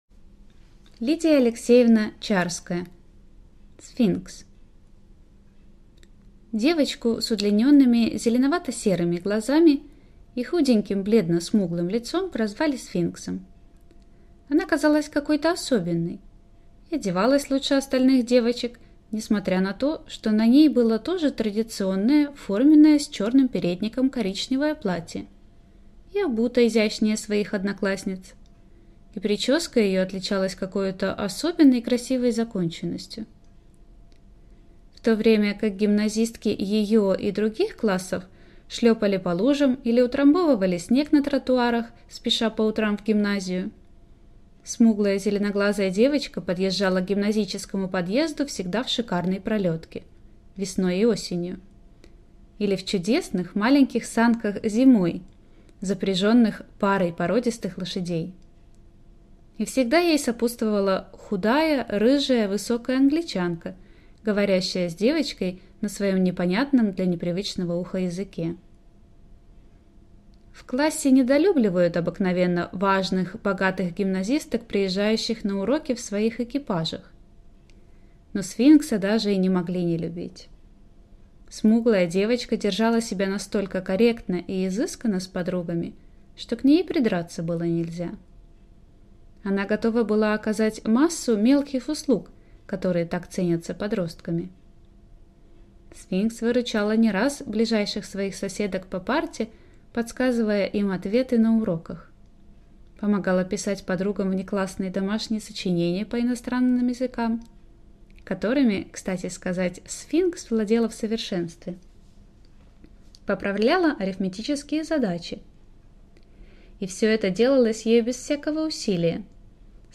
Аудиокнига Сфинкс | Библиотека аудиокниг